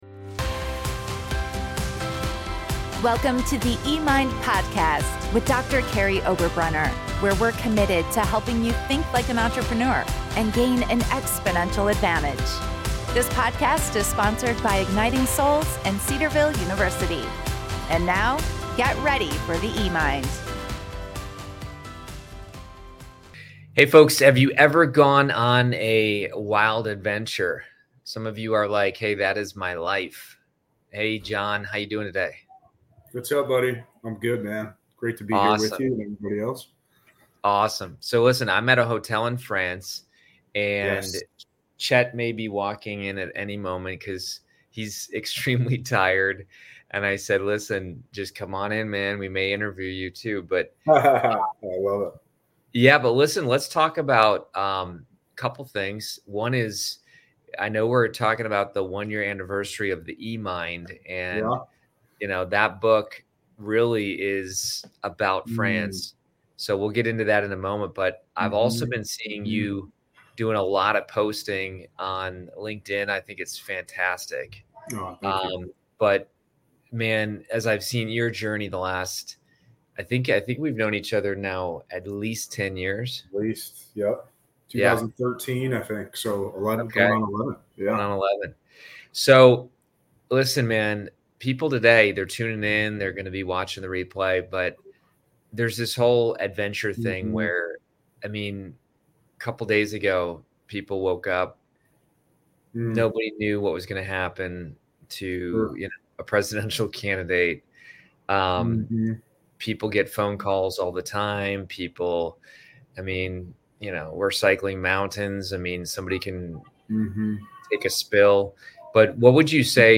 Live from France.